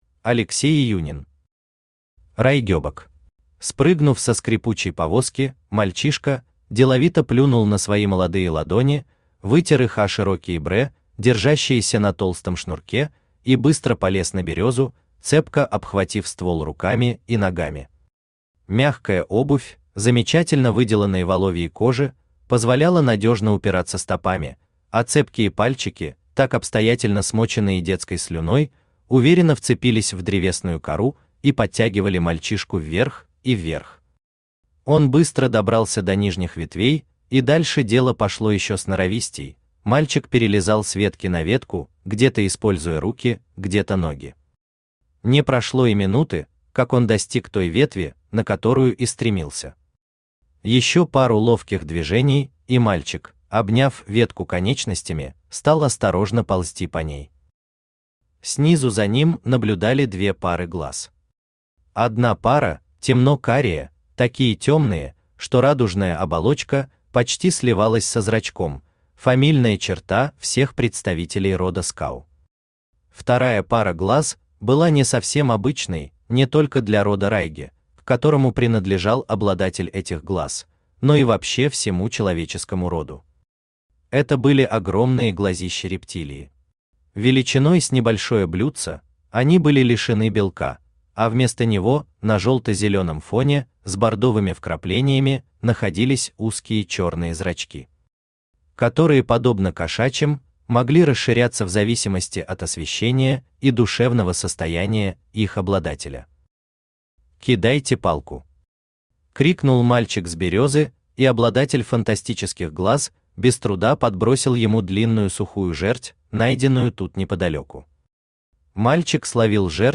Аудиокнига Райгебок | Библиотека аудиокниг
Aудиокнига Райгебок Автор Алексей Владимирович Июнин Читает аудиокнигу Авточтец ЛитРес.